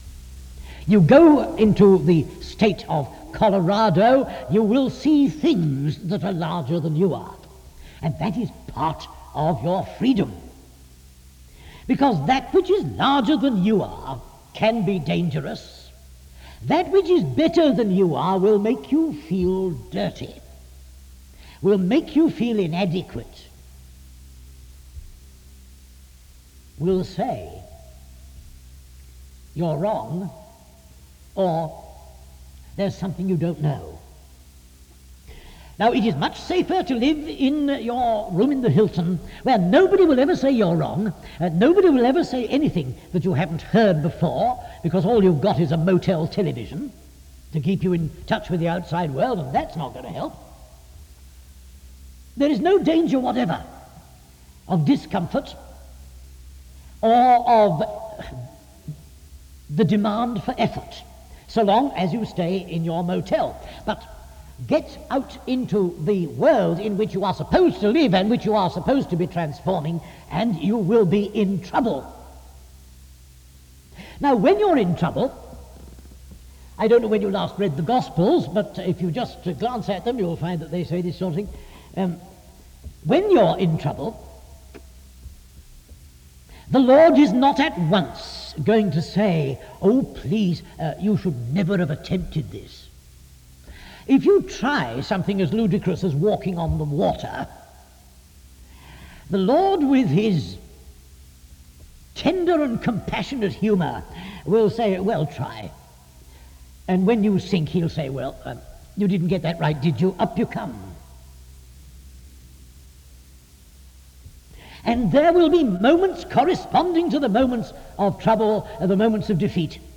SEBTS Spring Lecture